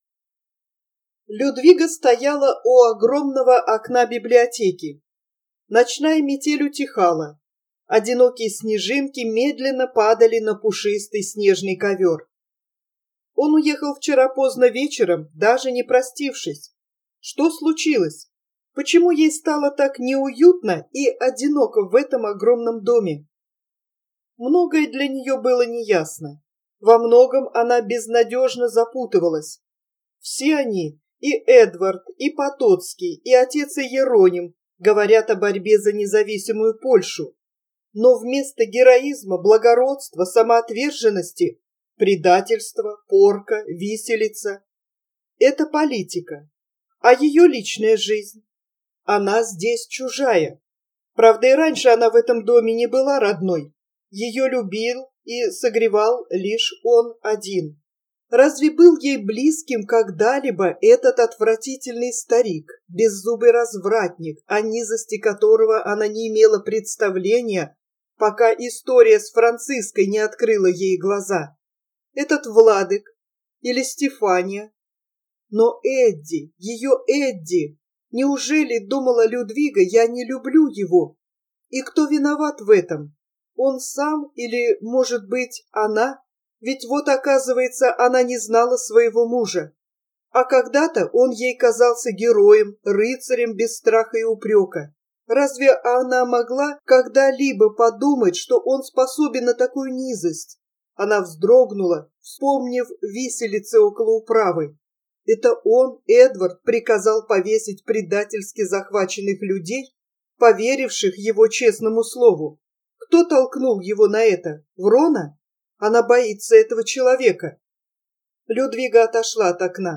Аудио роман советского писателя XX века Николая Алексеевича Островского "Рождённые бурей", 1934 - 1936 годы написания, Сочи - Москва.